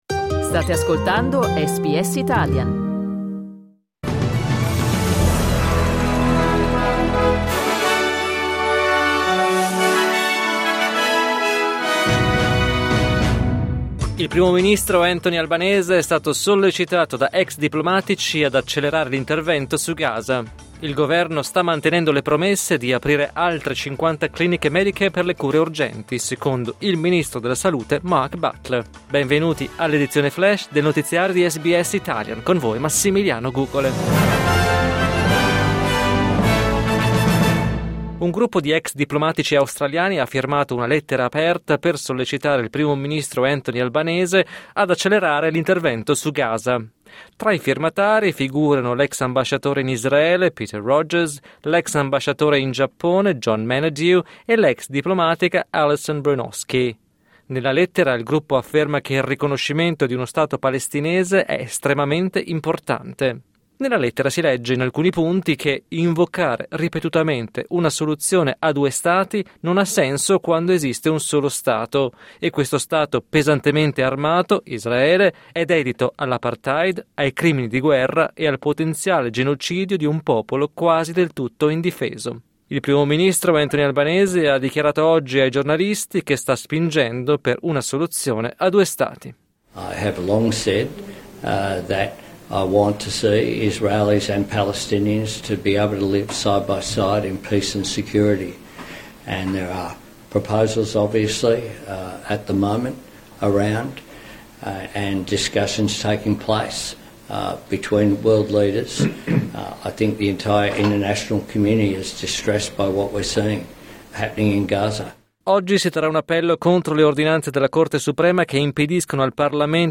News flash giovedì 7 agosto 2025
L’aggiornamento delle notizie di SBS Italian.